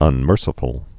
(ŭn-mûrsĭ-fəl)